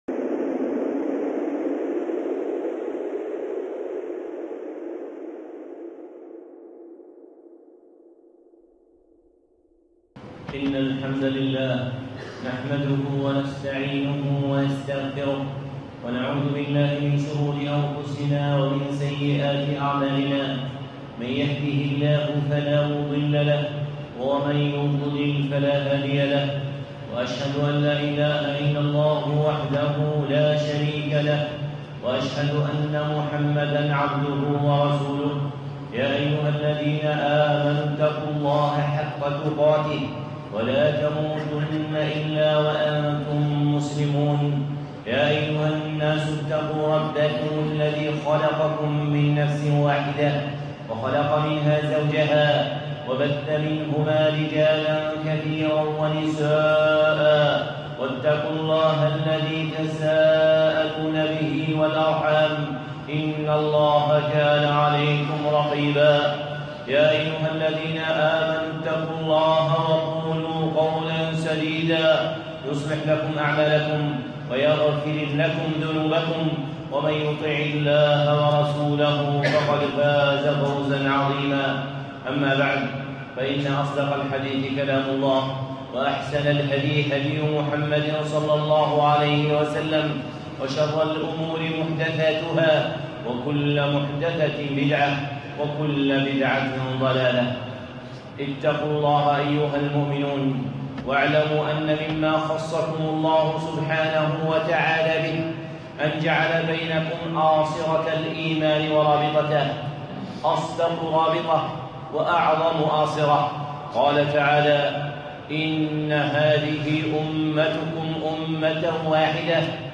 خطبة (الدعاء للمسلمين) الشيخ صالح العصيمي